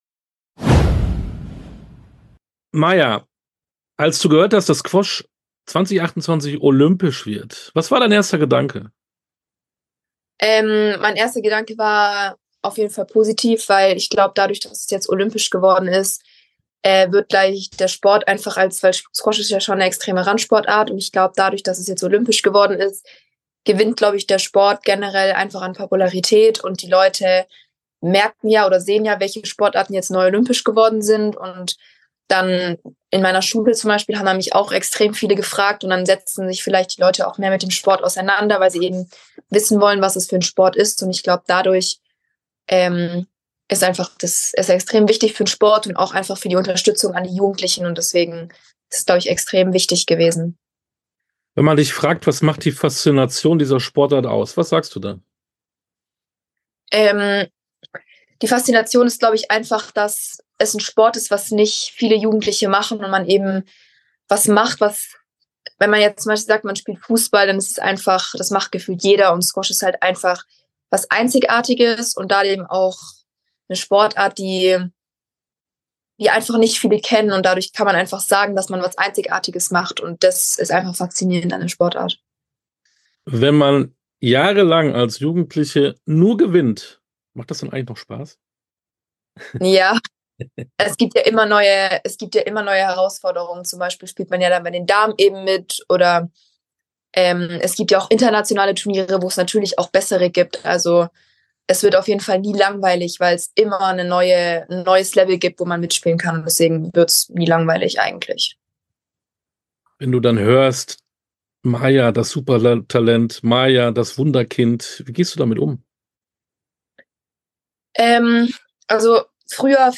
Interviews in voller Länge